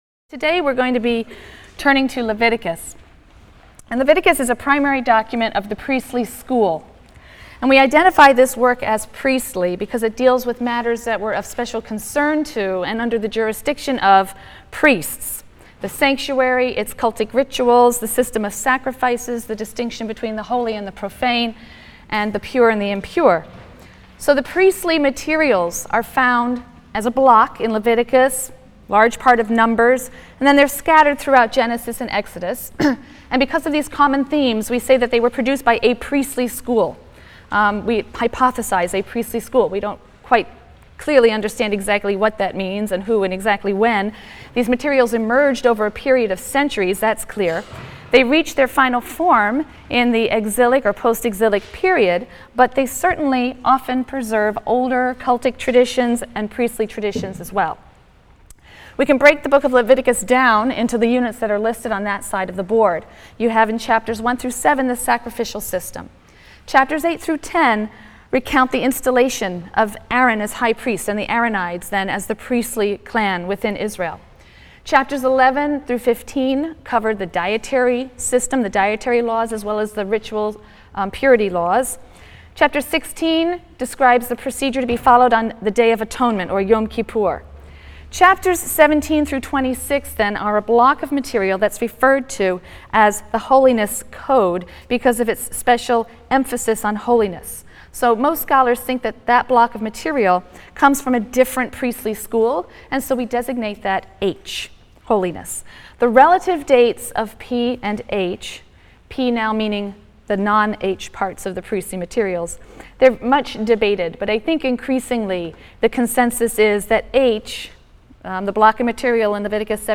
RLST 145 - Lecture 9 - The Priestly Legacy: Cult and Sacrifice, Purity and Holiness in Leviticus and Numbers | Open Yale Courses